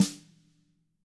Index of /90_sSampleCDs/ILIO - Double Platinum Drums 1/CD4/Partition B/WFL SNRD